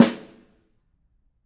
Snare2-HitSN_v7_rr2_Sum.wav